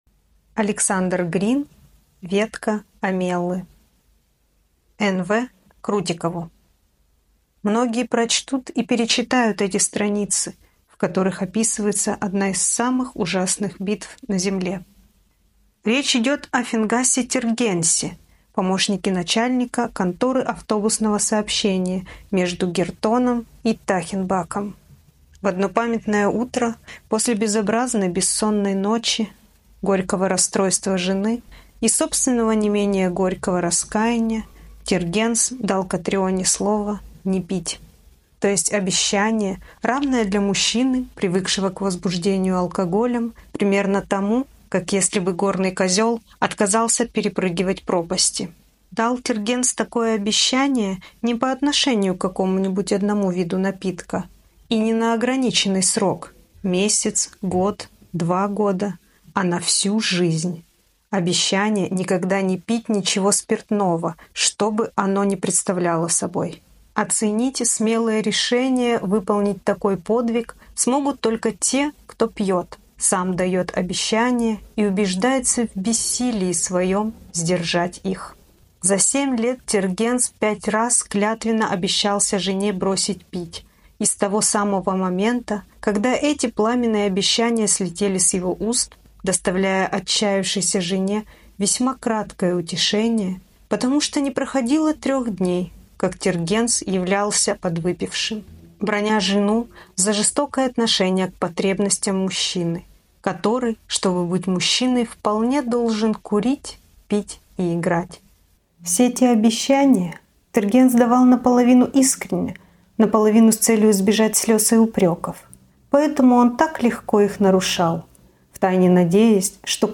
Сказка